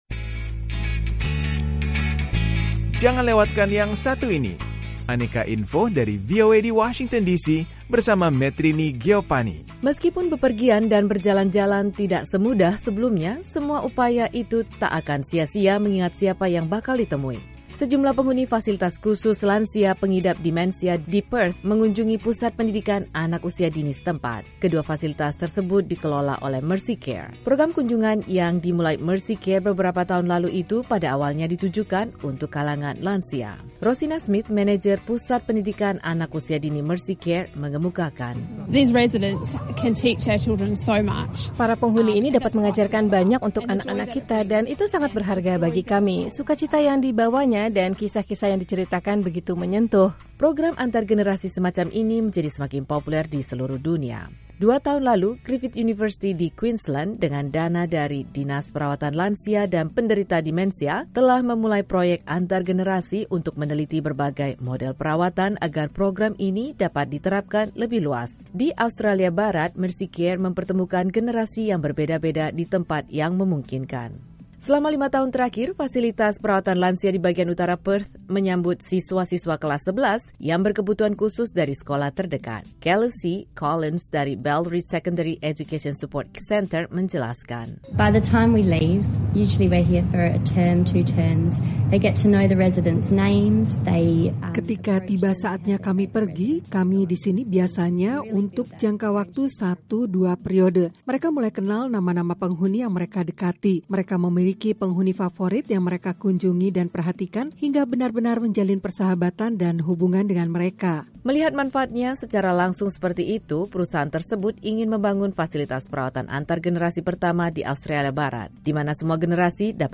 Laporan Associated Press